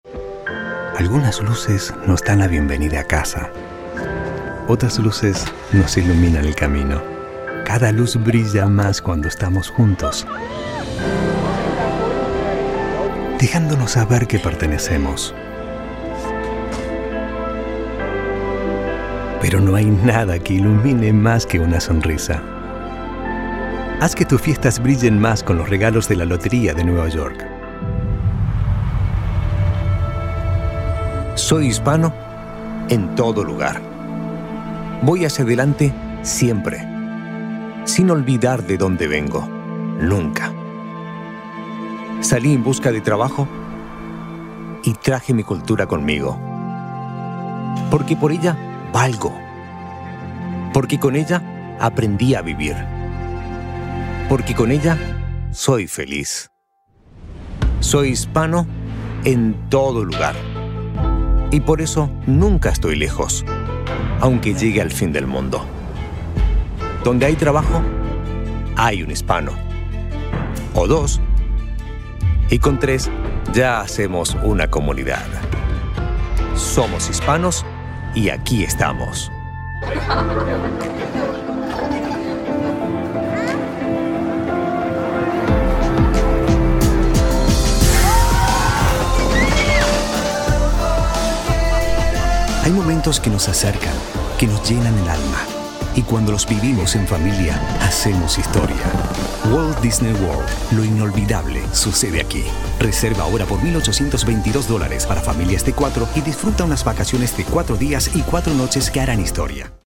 Warm, Personal, Conversational.
Commercial